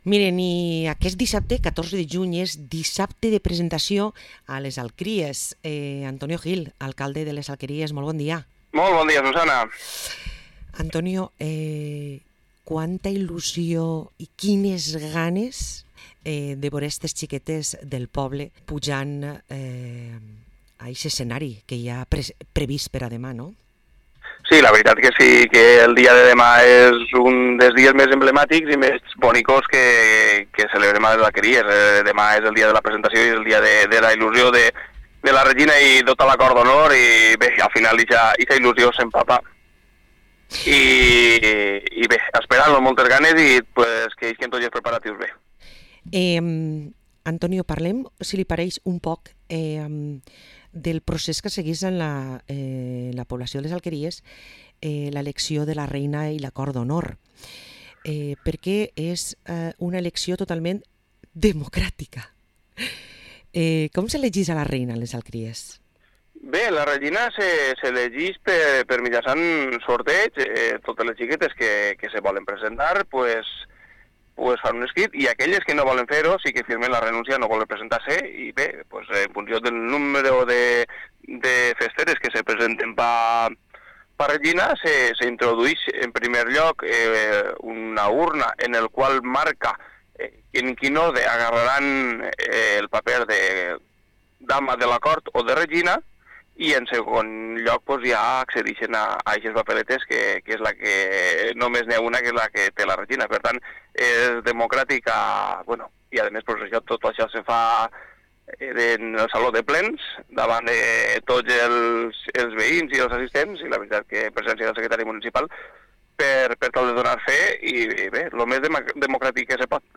Parlem amb l´alcalde d´Alqueries, Antonio Gil